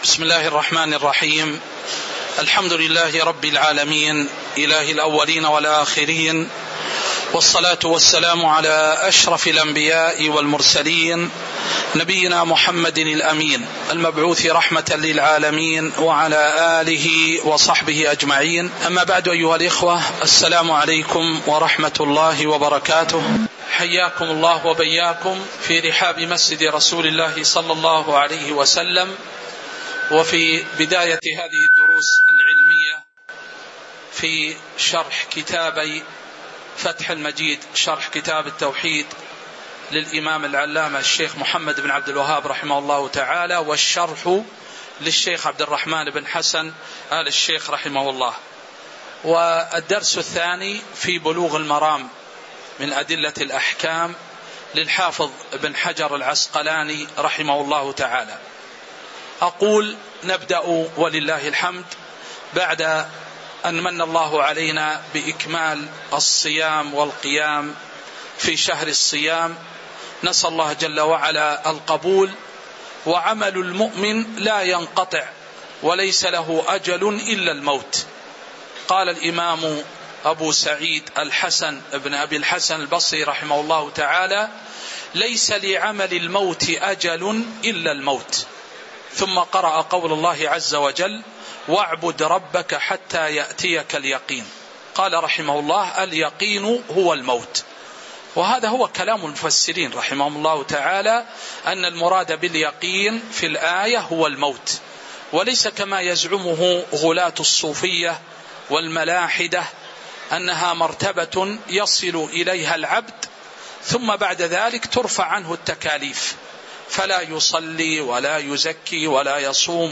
تاريخ النشر ٣ شوال ١٤٤٤ هـ المكان: المسجد النبوي الشيخ